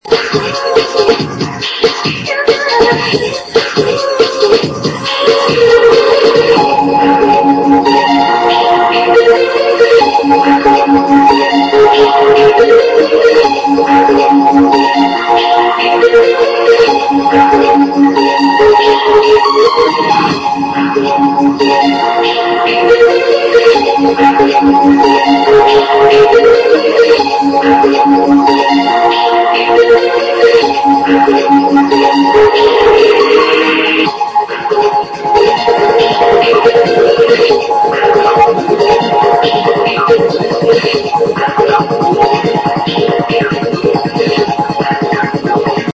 I recorded this Trance song off the Radio around 1999...